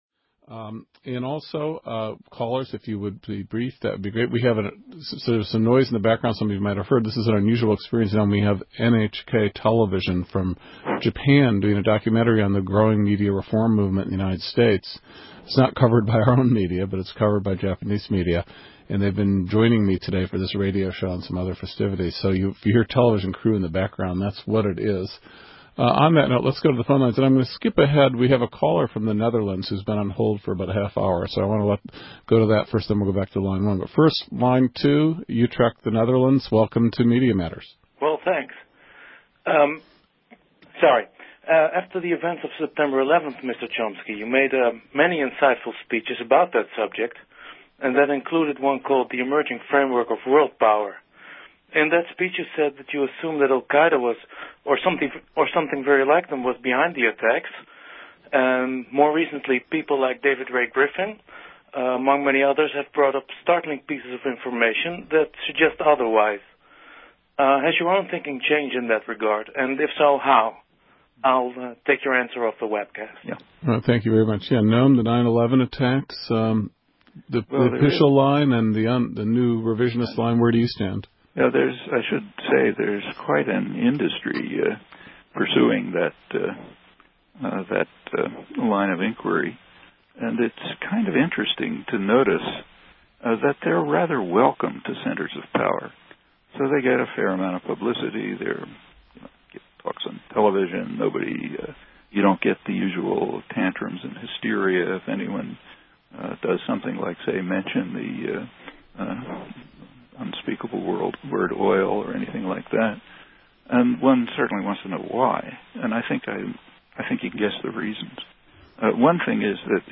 Chomsky's controversial statement about the 9/11 Truth Movement (clip from February 26, 2006 program):